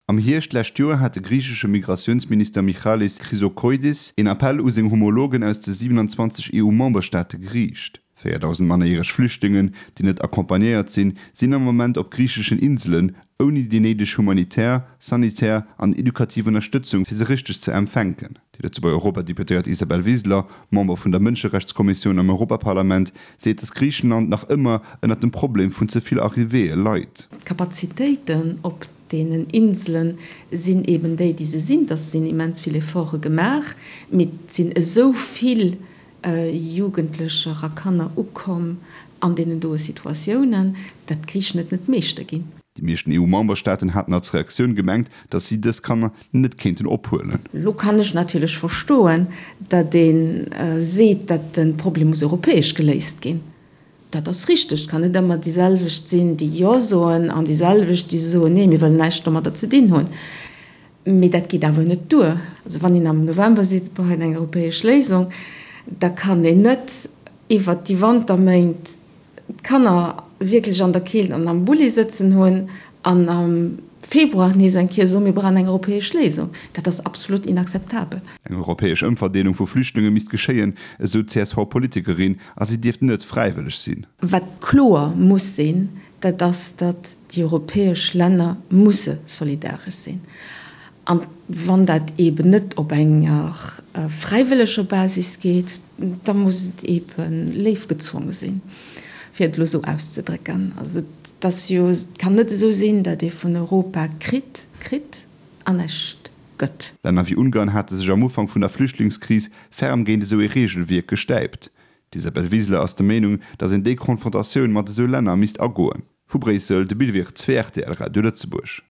[Reportage] Isabel Wiseler: EU-Memberstaate misste gezwonge ginn, mannerjäreg Flüchtlingen opzehuelen
Dëse Reportage gouf fir RTL Radio Lëtzebuerg produzéiert, an ass de 19. Februar iwwerdroe ginn.